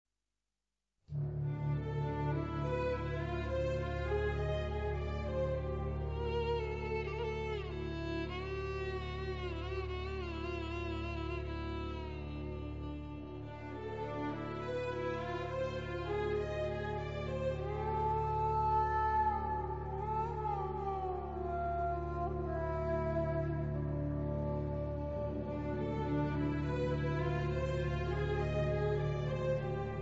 Recorded in Cairo and London